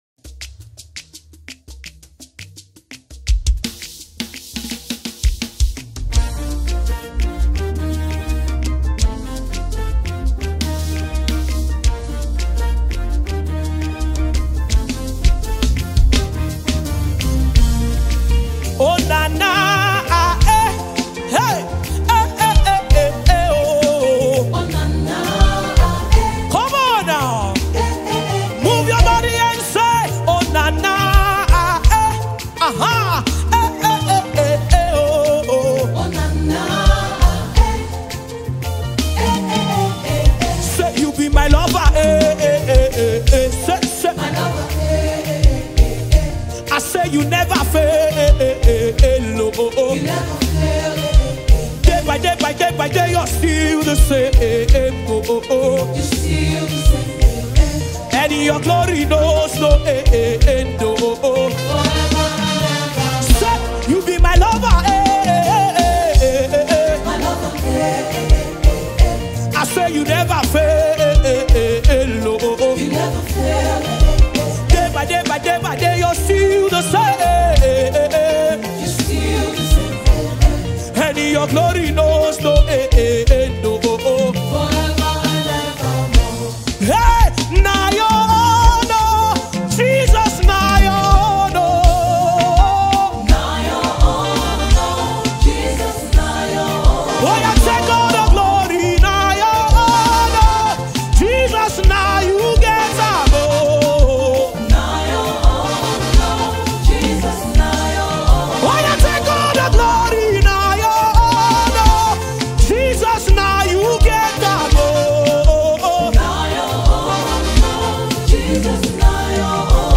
Talented Nigerian gospel singer and songwriter